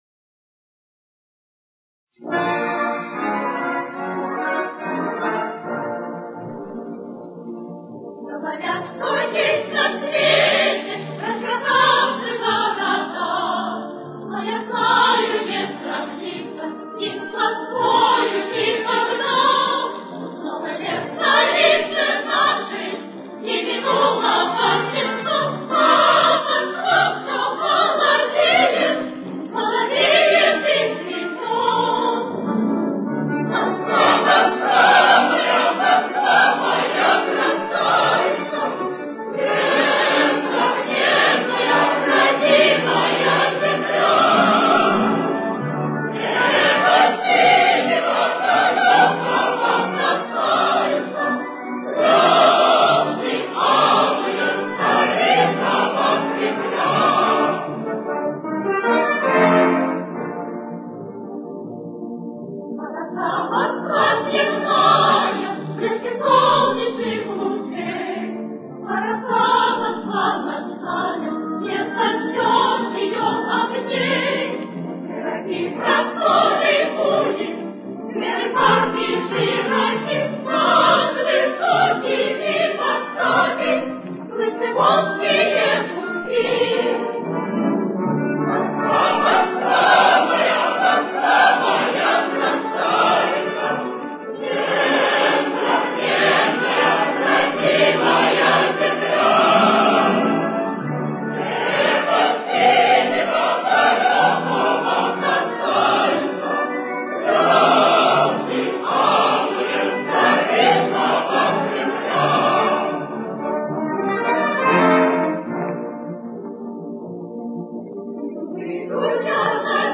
с очень низким качеством (16 – 32 кБит/с)
Темп: 156.